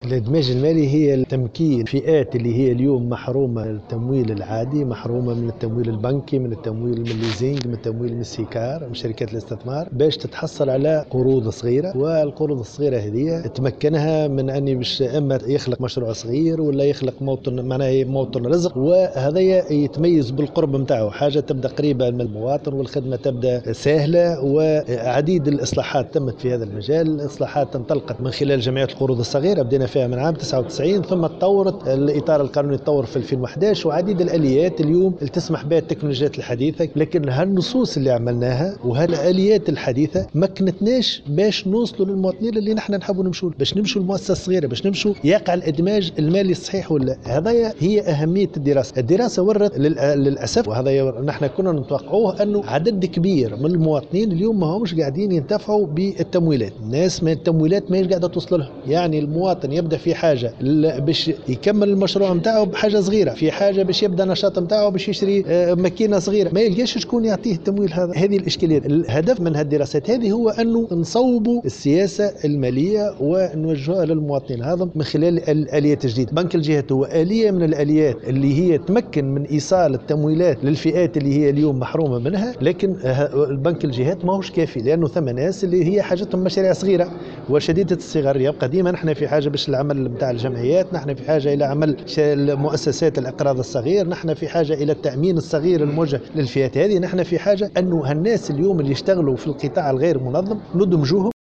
أكد وزير المالية رضا شلغوم في تصريح لمراسلة الجوهرة "اف ام" اليوم الثلاثاء أن استراتيجية الإدماج المالي تهدف إلى تمكين الفئات المحرومة من التمويل العادي والبنكي وتمويل شركات الاستثمار وشركات الايجار المالي من التحصل على قروض صغرى تمكنها من خلق مشاريع صغرى وموارد رزق.